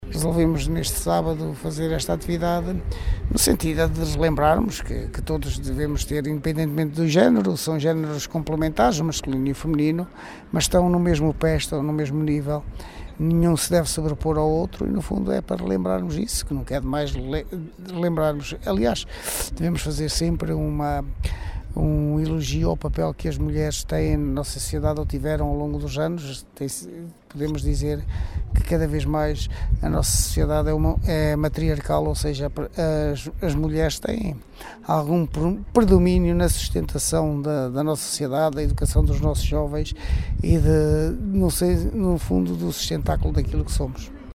Uma iniciativa da Câmara Municipal, depois de no dia 25 se ter assinalado o Dia Internacional pela Eliminação da Violência Contra as Mulheres, onde o objetivo foi sensibilizar a população para a importância de uma vida igualitária entre homens e mulheres, como explica Carlos Barroso, vice-presidente do concelho.